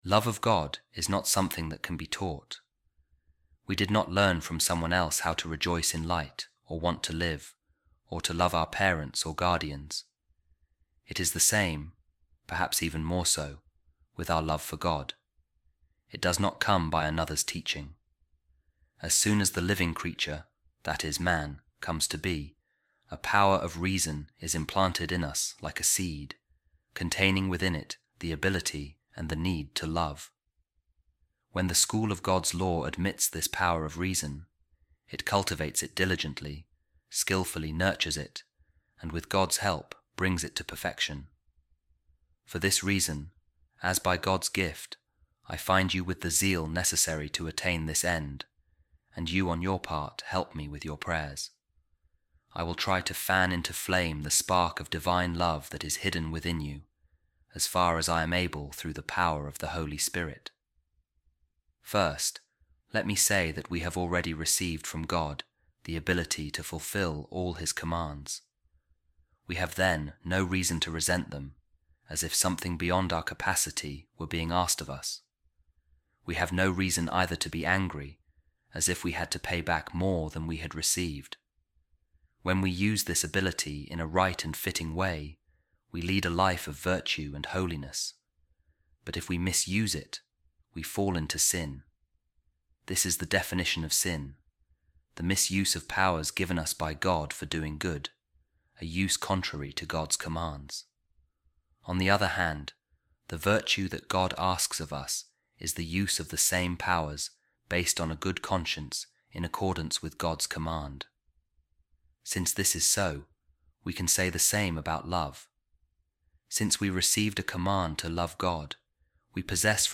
A Reading From The Longer Rules Of Saint Basil The Great | We Possess An Inborn Power Of Loving